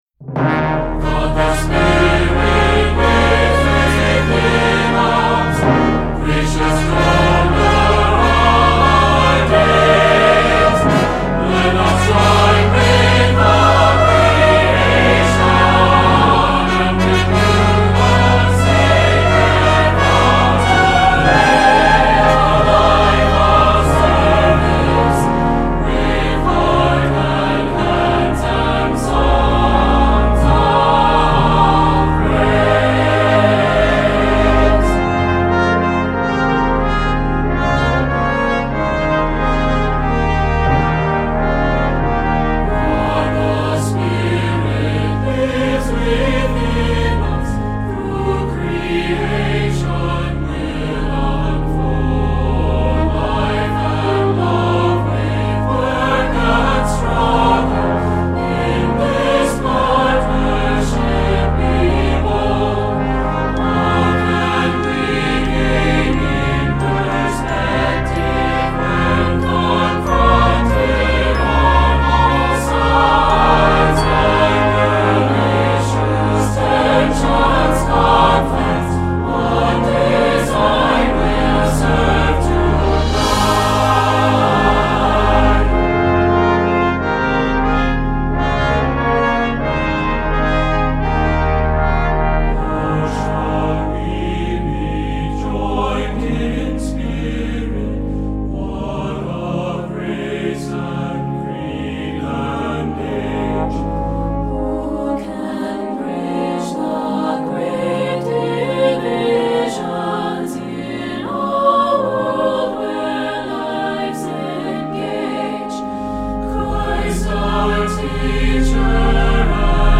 Voicing: SATB and Organ